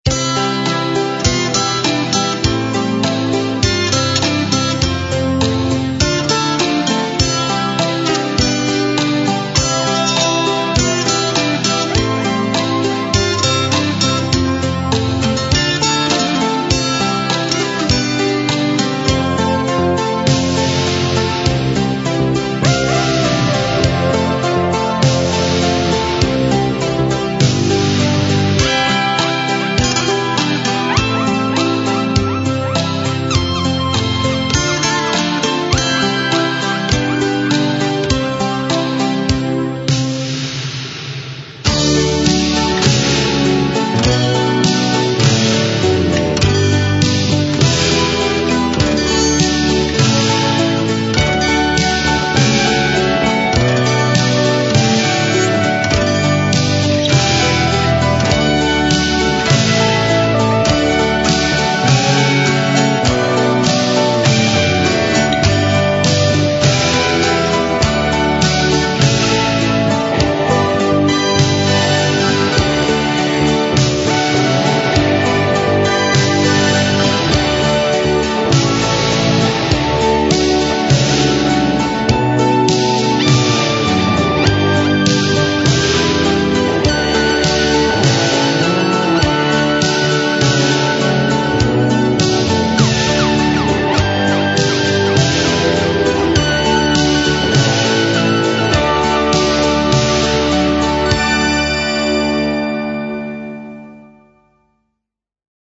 pomalu, sólo španělka, klávesy